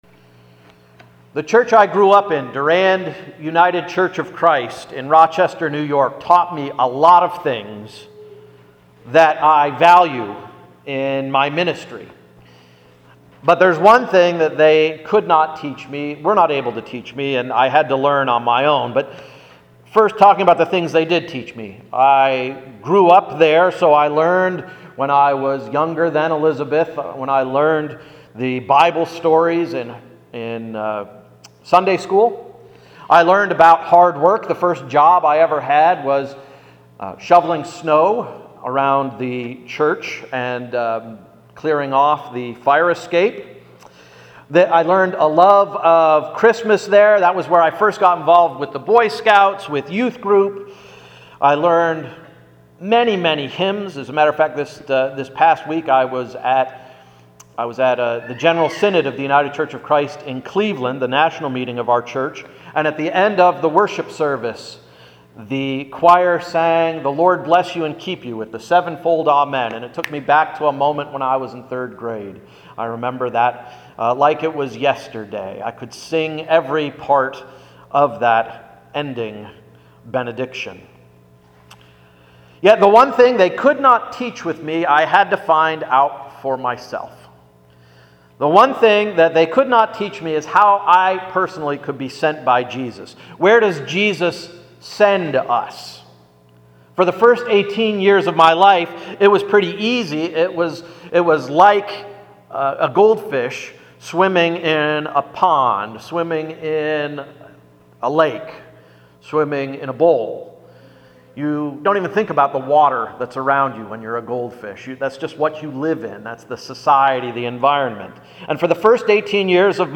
Sermon of July 5th–“Sent-er Field”